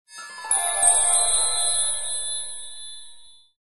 Звуки волшебной палочки
Звук волшебных колокольчиков